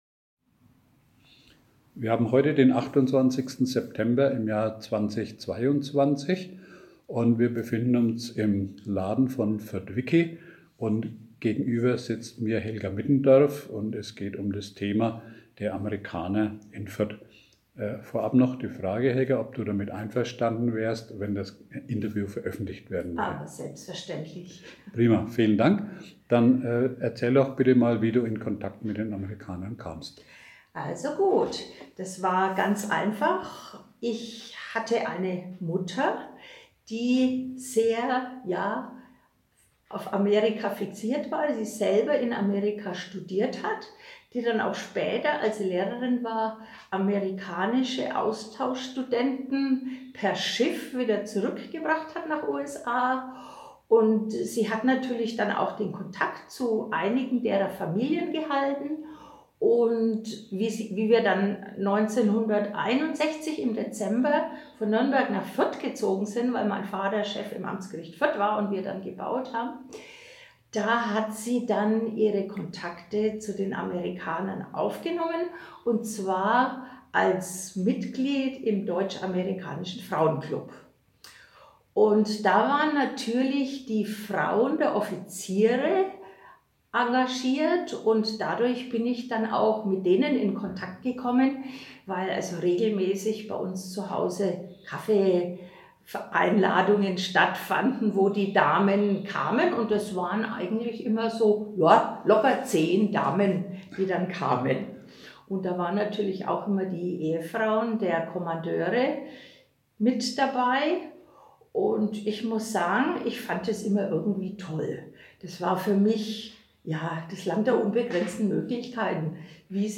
Zeitzeugenberichte